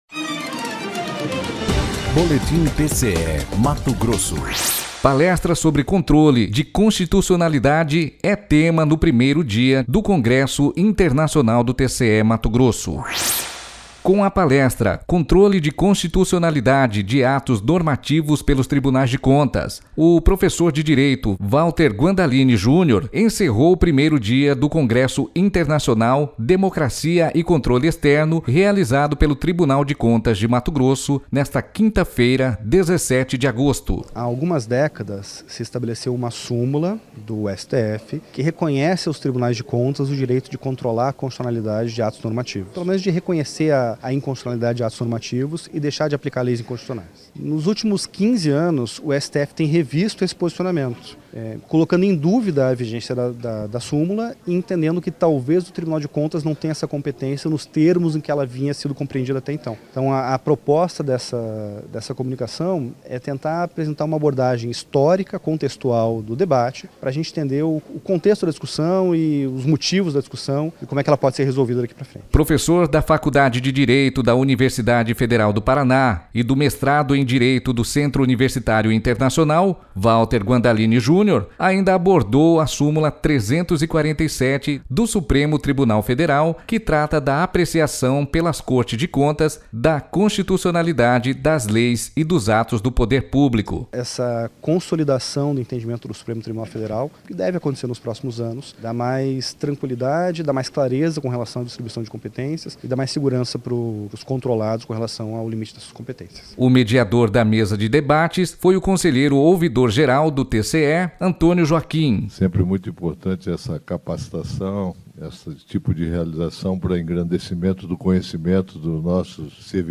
Sonora: Antonio Joaquim - ouvidor-geral do TCE
Os vídeos completos das palestras do Congresso Internacional Democracia e Controle Externo estão disponíveis no canal do Tribunal no youtube, no endereço TCE Mato Grosso.//